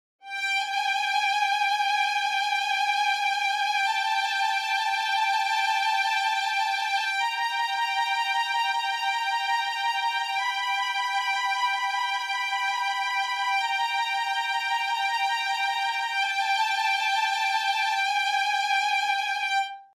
Ensemble legato
When using half-step and full-step trill, it sounds really great, relaxed and most of all - realistic. But when stepping a little further, the realism stops and it's pure synthesis.